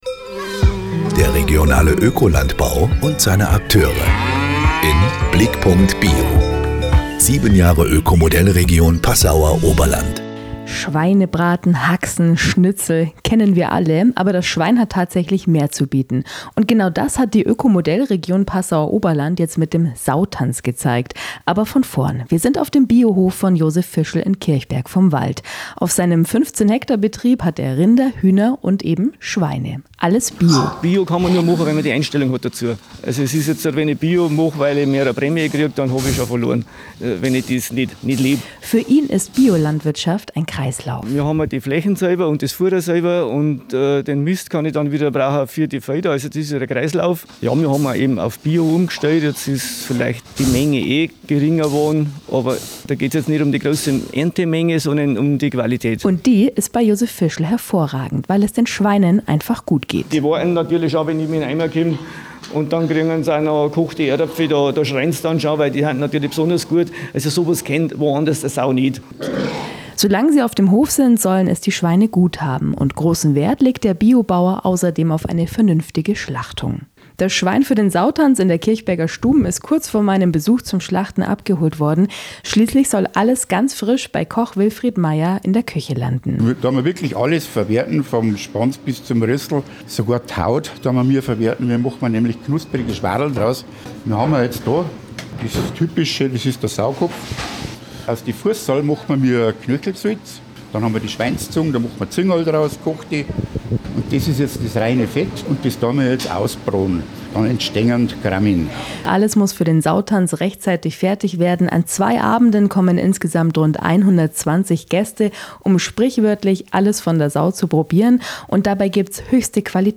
Geschichten aus der Öko-Modellregion Passauer Oberland bei UNSER RADIO